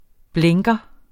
Udtale [ ˈblεŋgʌ ]